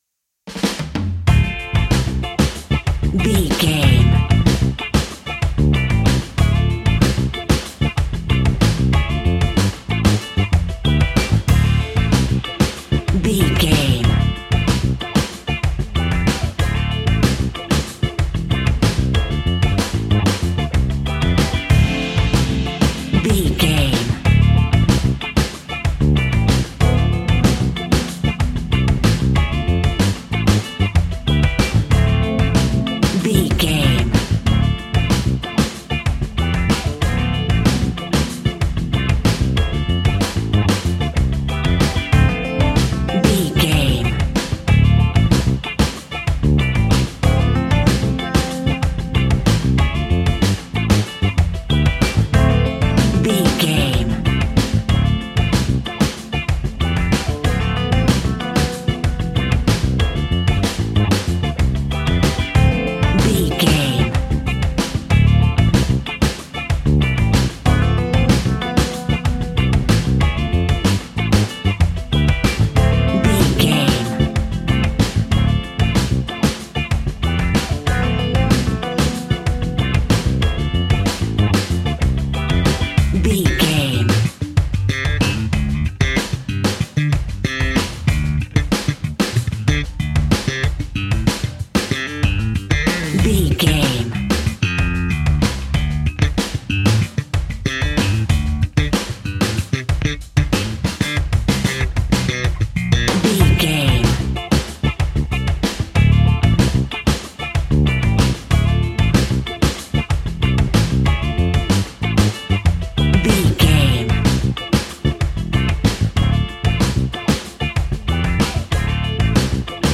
Aeolian/Minor
lively
electric guitar
electric organ
drums
bass guitar
saxophone
percussion